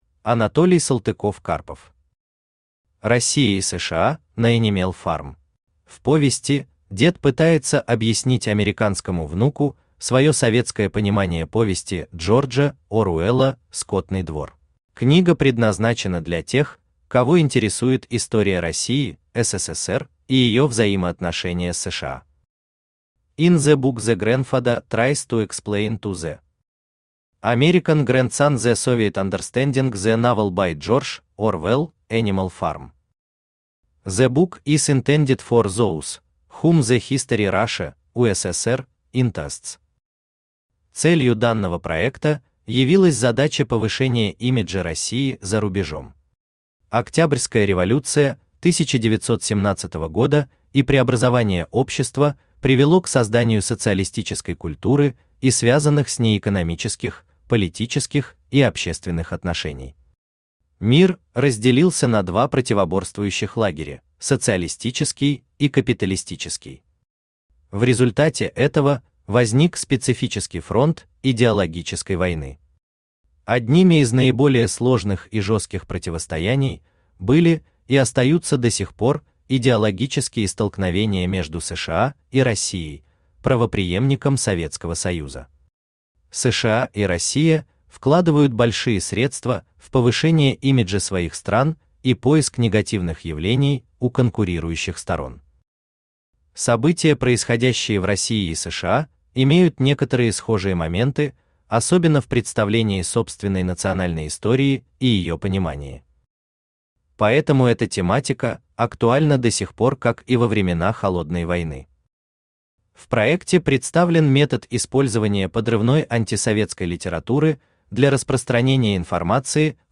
Аудиокнига Россия и США на Энимел фарм | Библиотека аудиокниг
Aудиокнига Россия и США на Энимел фарм Автор Анатолий Сергеевич Салтыков-Карпов Читает аудиокнигу Авточтец ЛитРес.